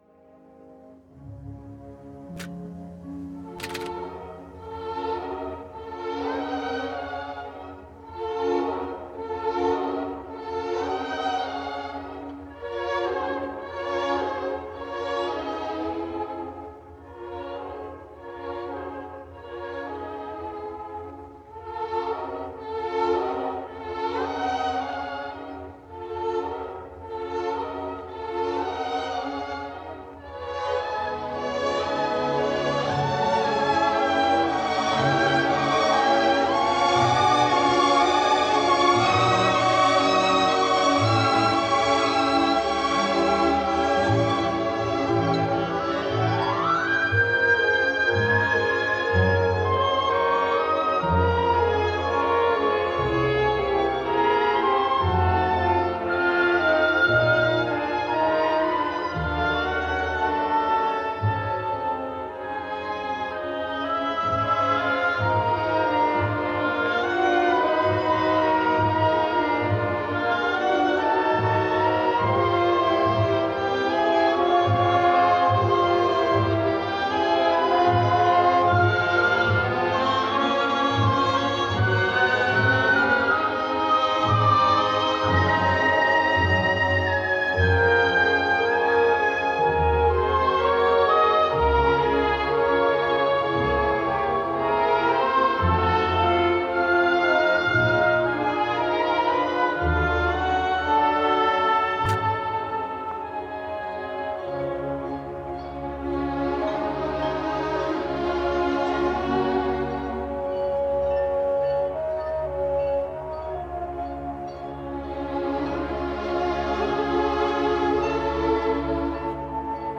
Romeo and Juliet, is an orchestral work composed by Pyotr Ilyich Tchaikovsky. It is styled an Overture-Fantasy, and is based on Shakespeare’s play of the same name.